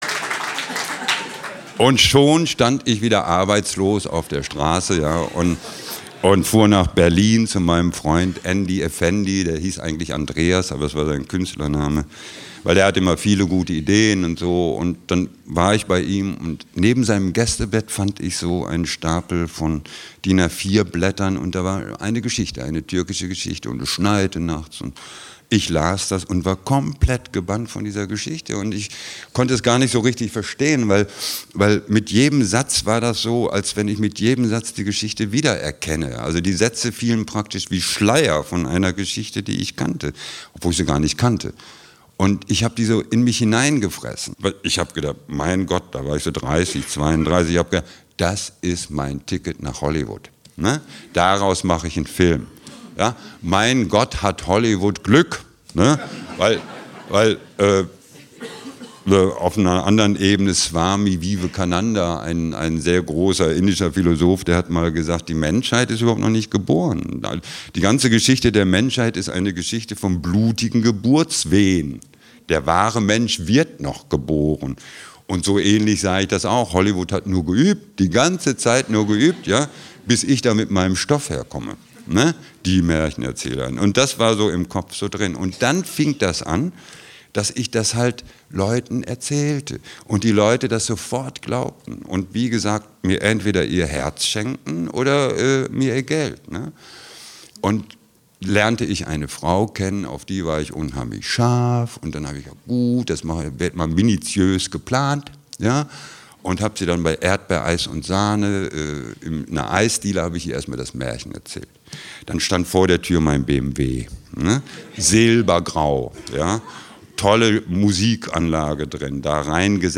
Die Märchentante, der Sultan, mein Harem und ich (Live-Lesung) 2 CDs Helge Timmerberg (Autor) Helge Timmerberg (Sprecher) Audio-CD 2014 | 1.